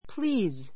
please 小 A1 plíːz プ り ー ズ 間投詞 どうぞ , すみませんが ⦣ 副 とする分類もある.